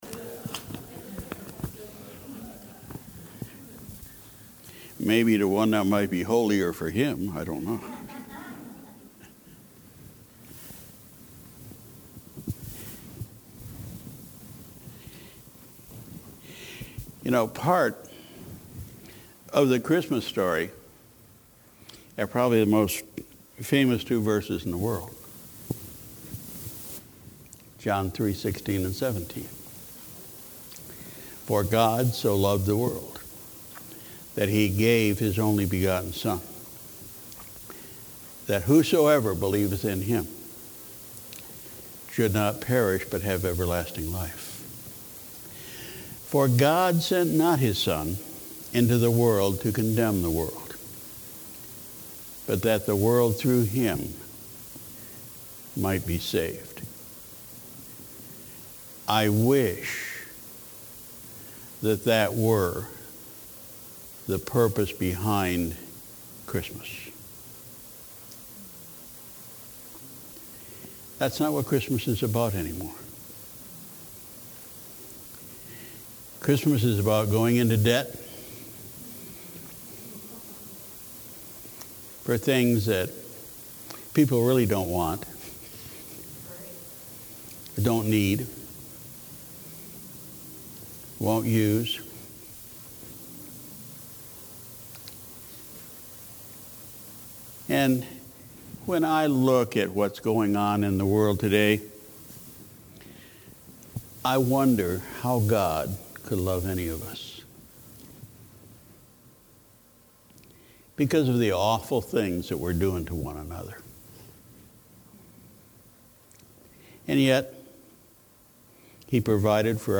Sunday, December 17, 2017 – Morning Service